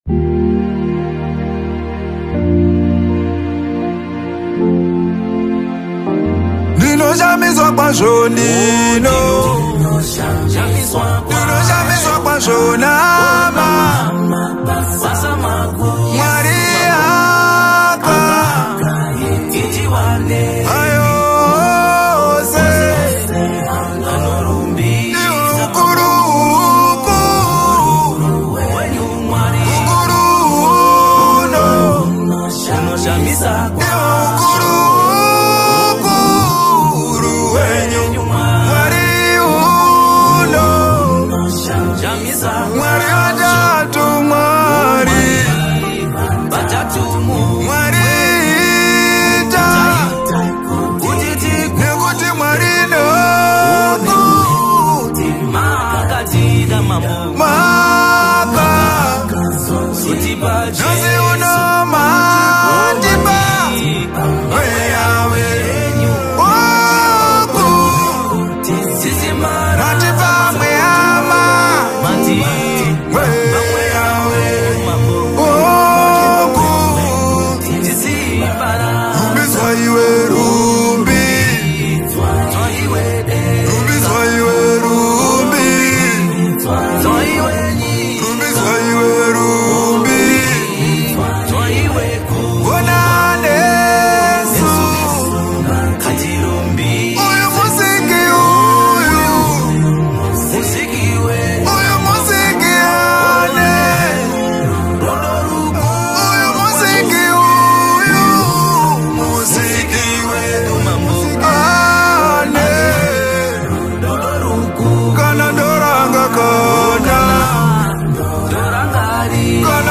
South African Gospel
Genre: Gospel/Christian.